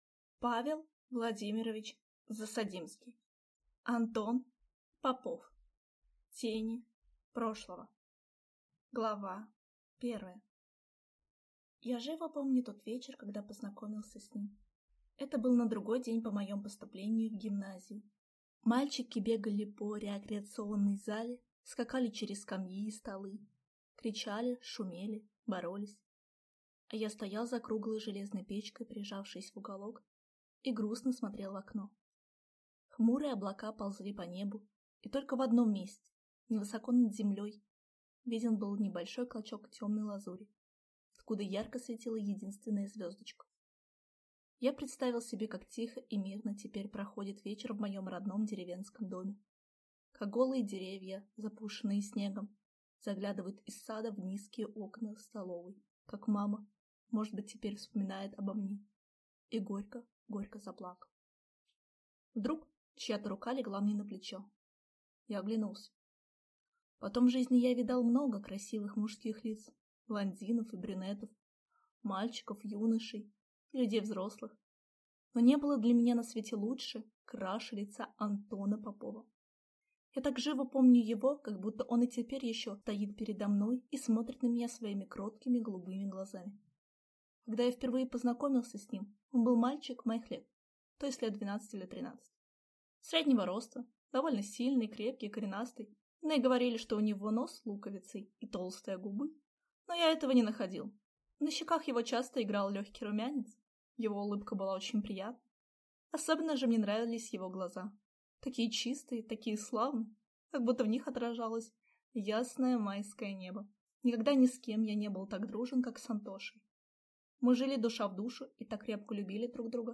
Аудиокнига Антон Попов